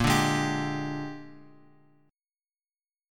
A#13 Chord
Listen to A#13 strummed